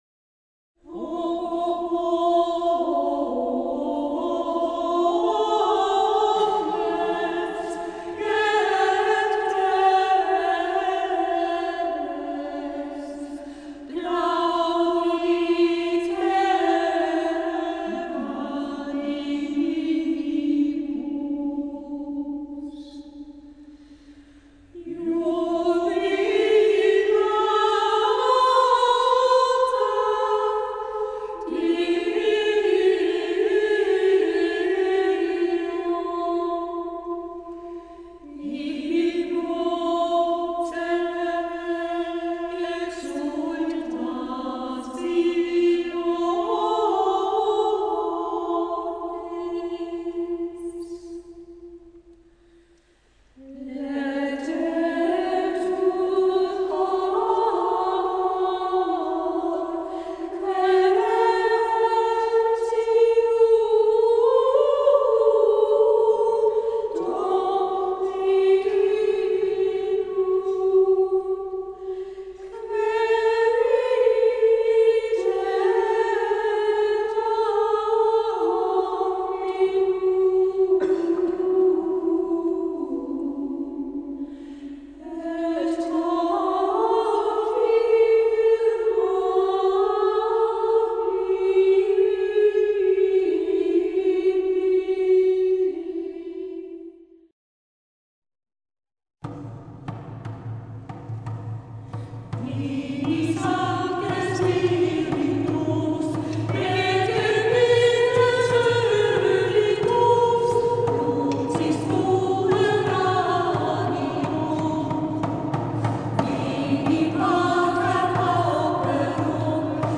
Choralkonzert in Villach-St. Jakob
Diese beiden Scholen gestalteten in der Stadthauptpfarrkirche Villach-St. Jakob ein schönen Abend mit gregorianischen Gesängen.
Medley Choralkonzert 3 MB Folge der angespielten Titel siehe oben